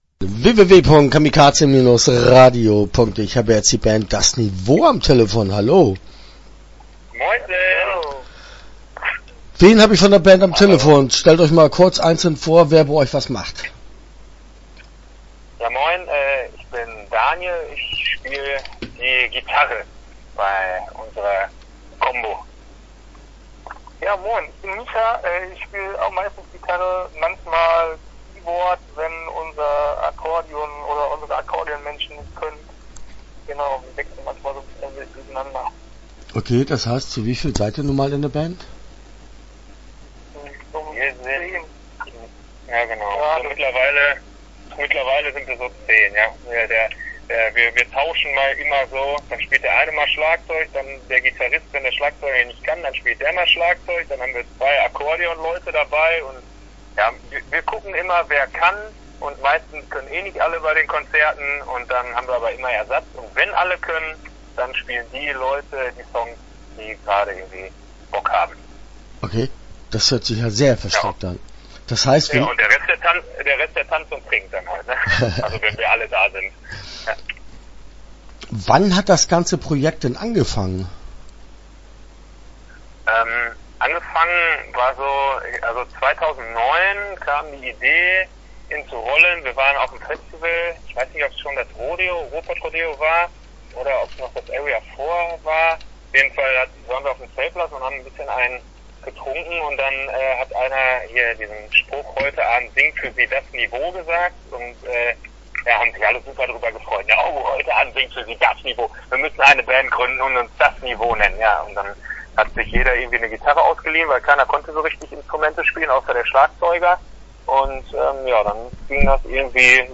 DAS NIWO - Interview Teil 1 (12:25)